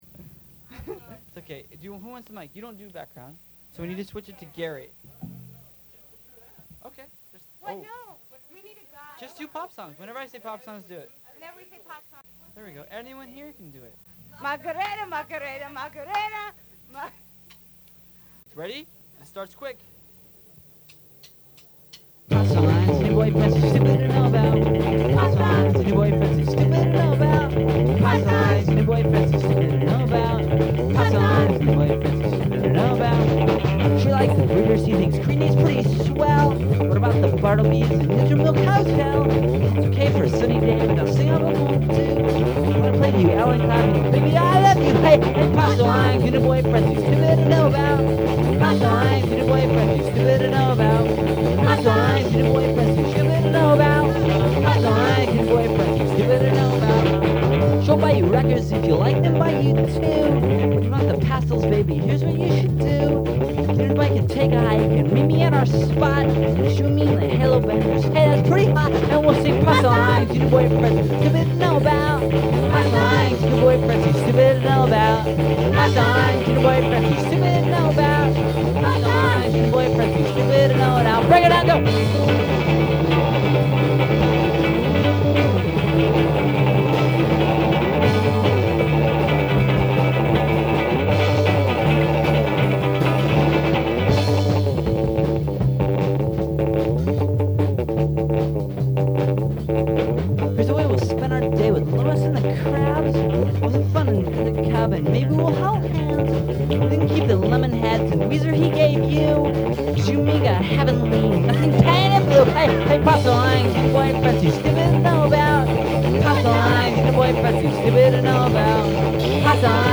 Here is a chaotic live recording of the song
additional vocals